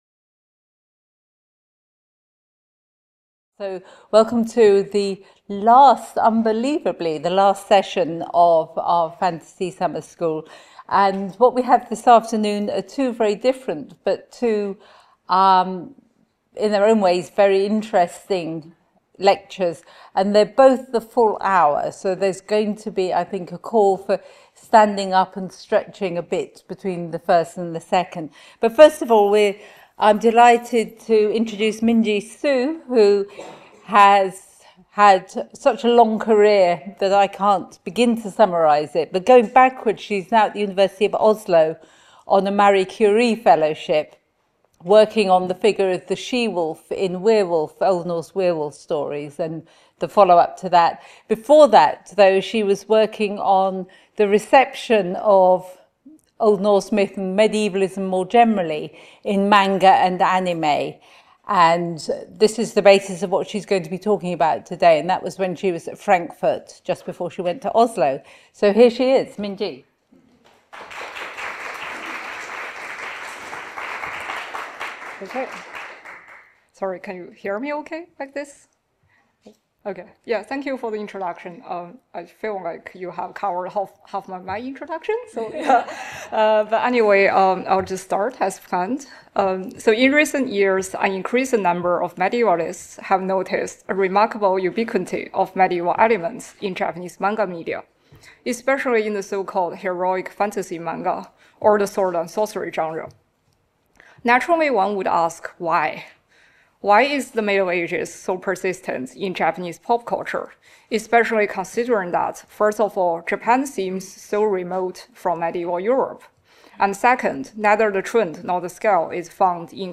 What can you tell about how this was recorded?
Part of the Bloomsbury-Oxford Summer School (23rd-25th September 2025) held at Exeter College.